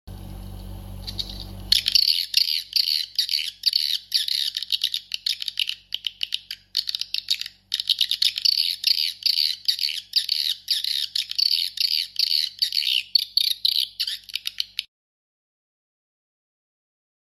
Audax AX 65 Piezo Tweeter [For sound effects free download
Audax AX-65 Piezo Tweeter [For Swiftlet Farming]